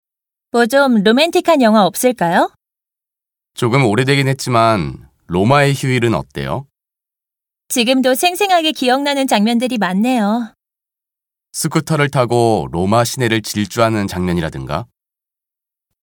韓国語ナレーション
とても聞きやすく、ナチュラルスピードで話されているので、学習教材として持ってこいです。会話のところは皆さん演技もしてくださっていて、聞いていて楽しい教材となっています。
こちらはウオーミングアップ！の30短文通しの音声であまり間を空けずテンポが良いです。